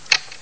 gear_shift.wav